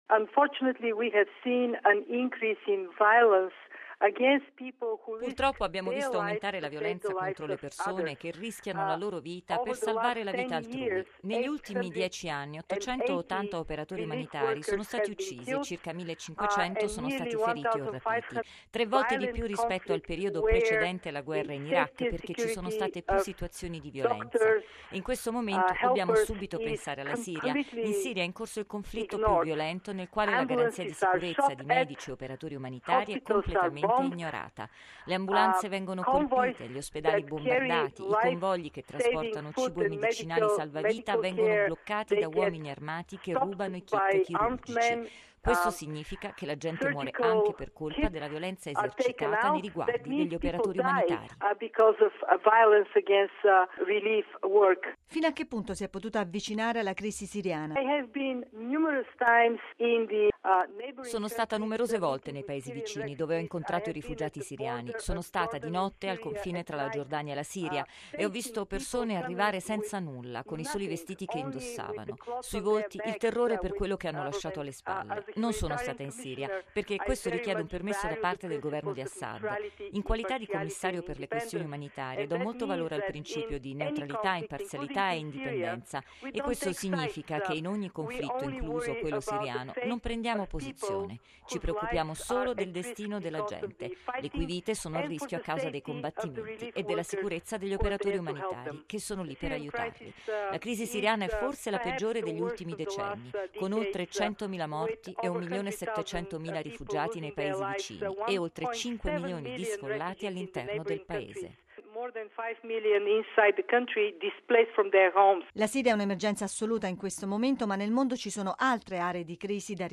Commissario europeo per la cooperazione internazionale, gli aiuti umanitari e la risposta alle crisi:
R. – Unfortunately we had seen an increasing...